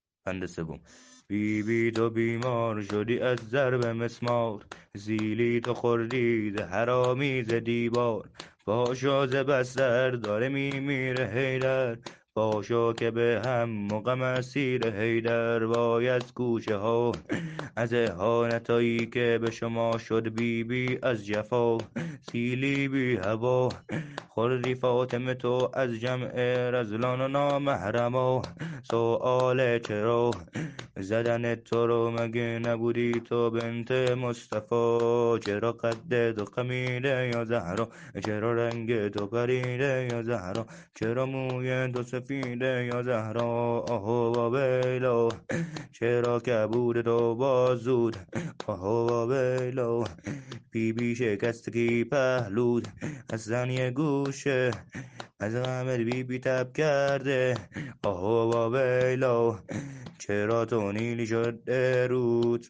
شور شهادت حضرت فاطمه زهرا (س) -(عصمتِ کبرا تویی همسر به مولا)